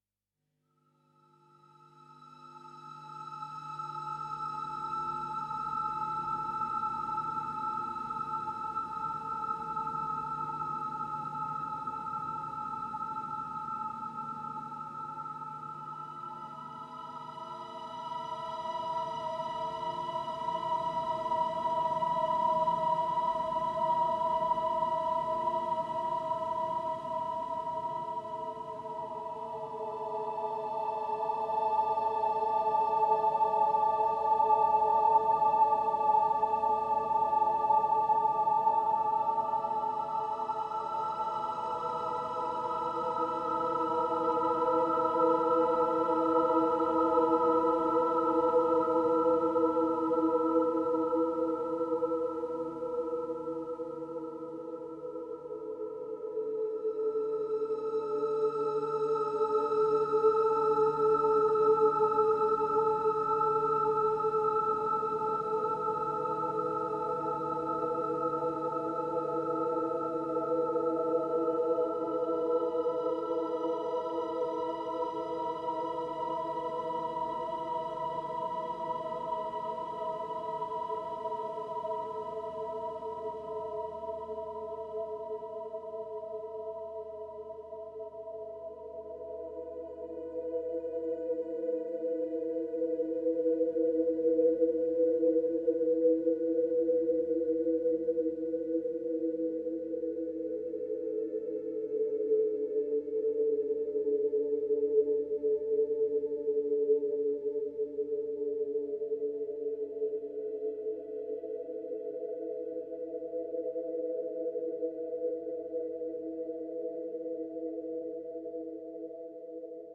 Spectral, high-pitched pads create a spooky atmosphere.